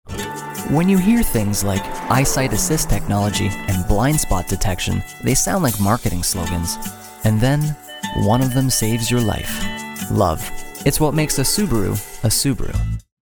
Male
Yng Adult (18-29), Adult (30-50)
Radio Commercials